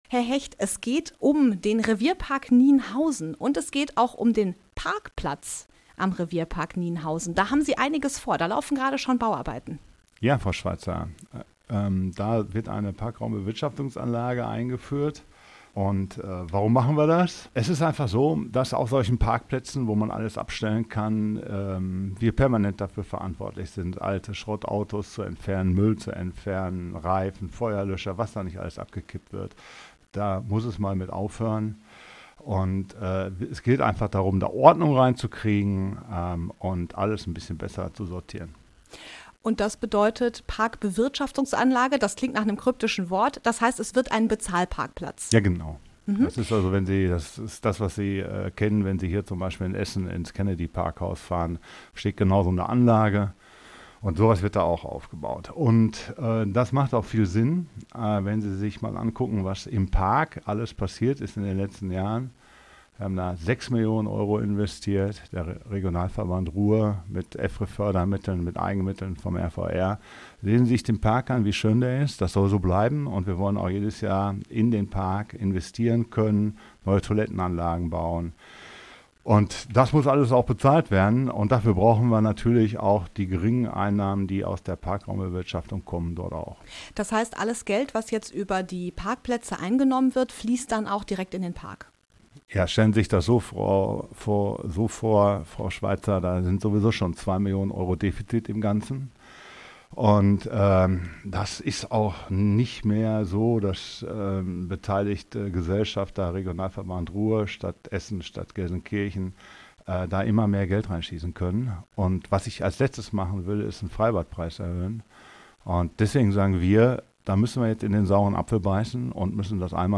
int-parkplaetze-revierpark-nienhausen-fuer-online.mp3